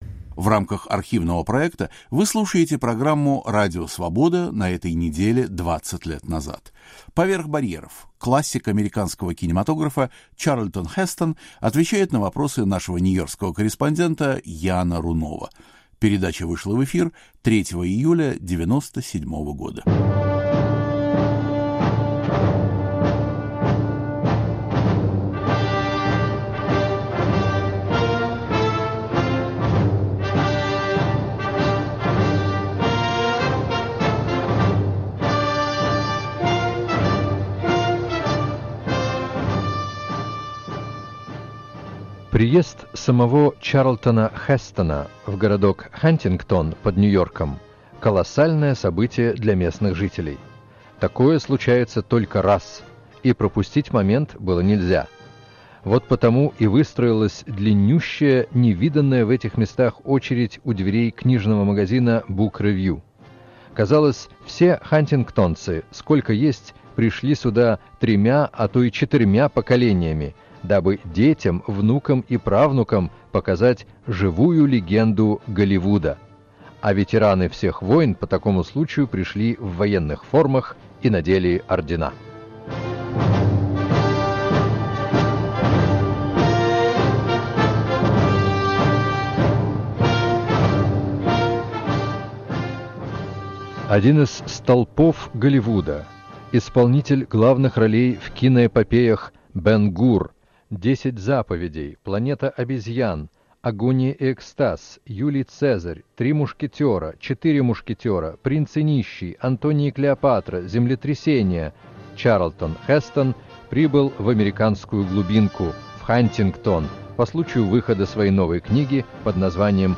Первое русское интервью звезды Голливуда Чарлтона Хестона.